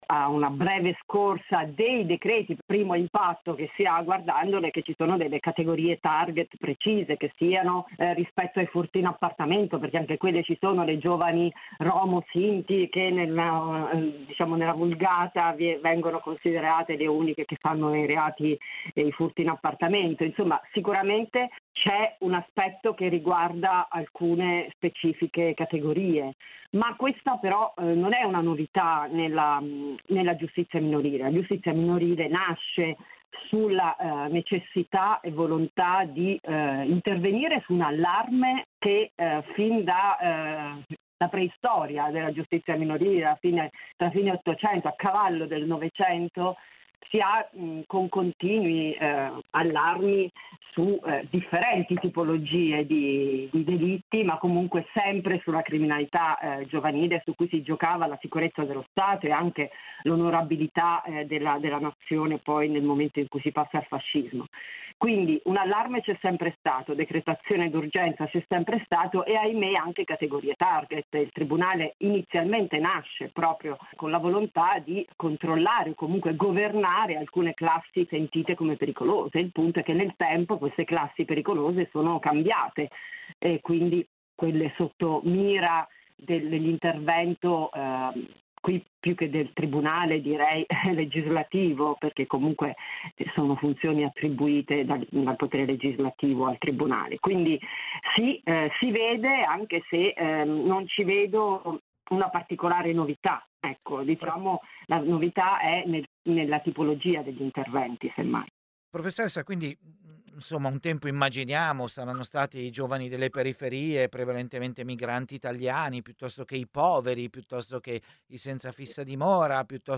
Il paradosso è che l’allarme sui casi di cronaca non corrisponde agli stessi dati del Ministero dell’Interno che ieri ha confermato la riduzione complessiva e specifica dei reati che dovrebbero essere oggetto dell’ennesimo pacchetto sicurezza. L'intervista